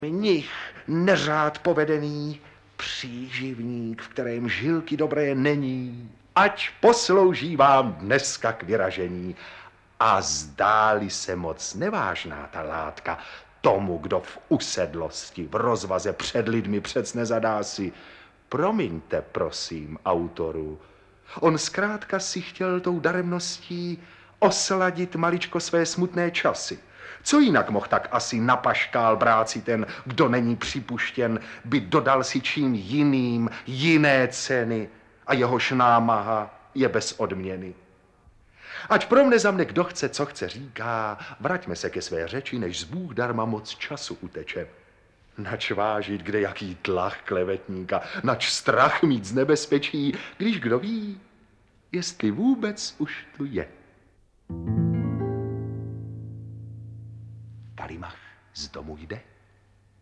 Audiobook
Read: Otakar Brousek